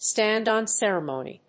stànd on céremony
発音